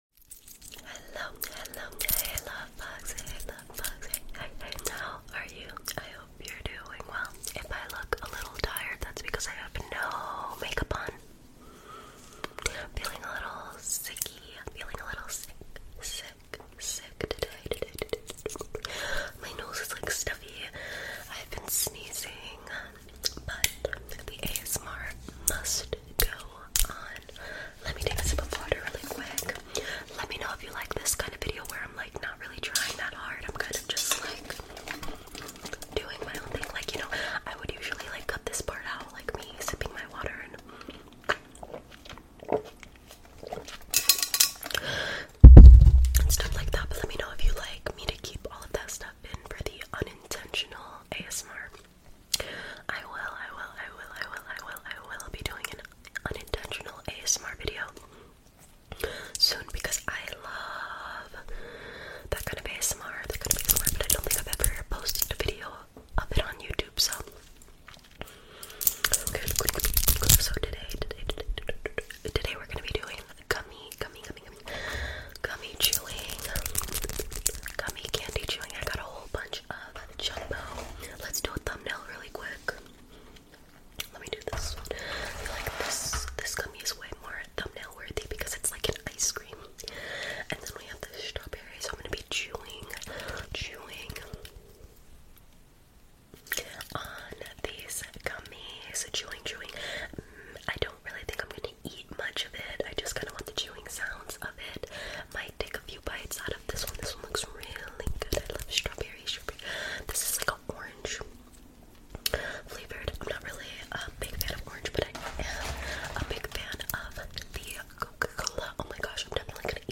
ASMR| Giant Gummy Chewing Mouth Sounds 🍓